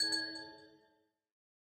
Minecraft Version Minecraft Version latest Latest Release | Latest Snapshot latest / assets / minecraft / sounds / block / amethyst / resonate1.ogg Compare With Compare With Latest Release | Latest Snapshot
resonate1.ogg